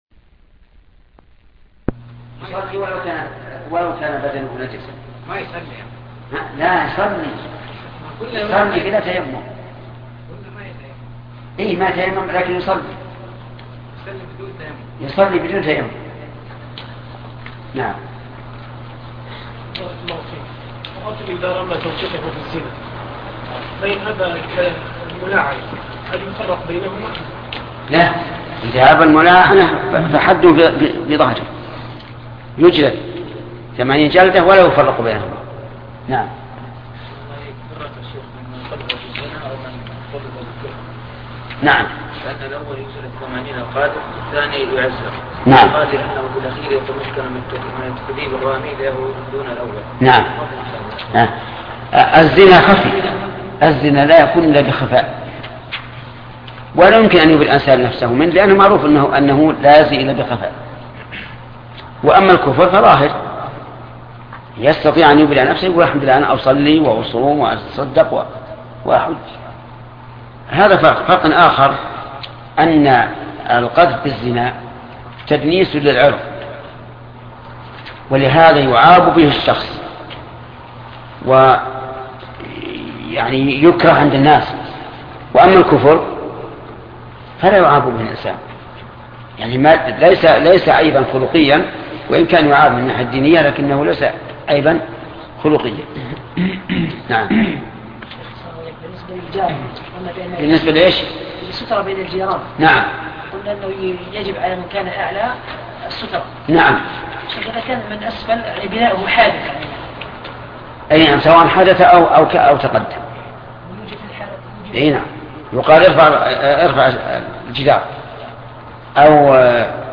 القواعد والأصول الجامعة والفروق والتقاسيم البديعة النافعة شرح الشيخ محمد بن صالح العثيمين الدرس 22